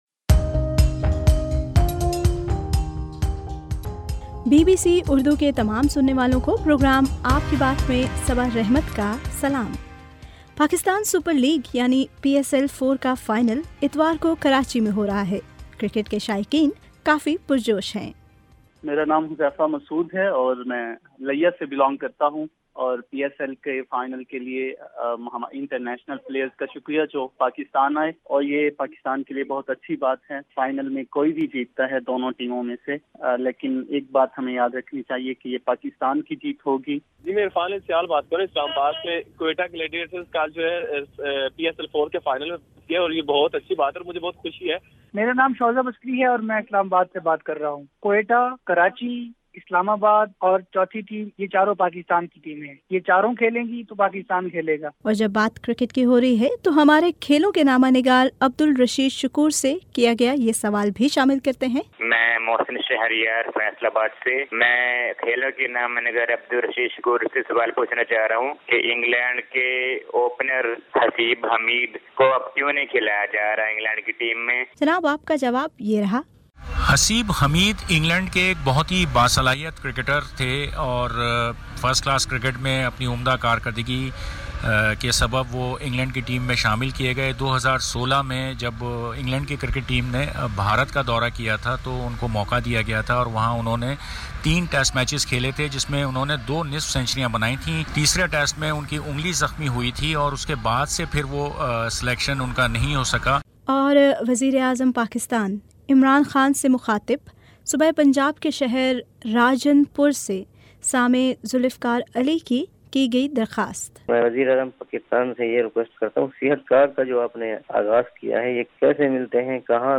آپ کے صوتی پیغامات پر ، مبنی پروگرام ” آپ کی بات ٌ